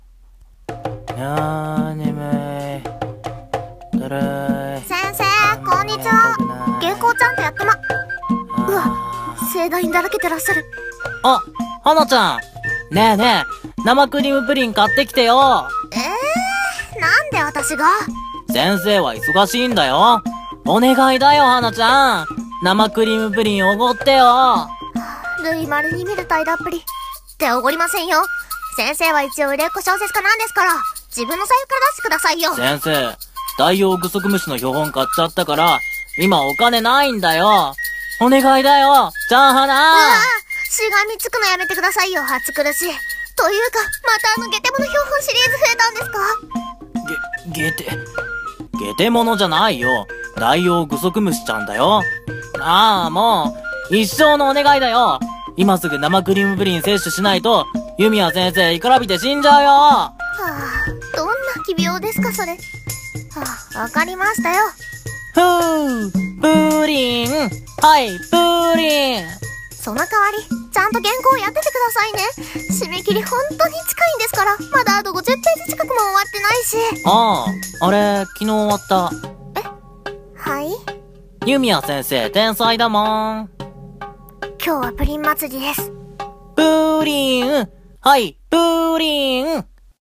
【声劇】天才作家と甘味なお昼時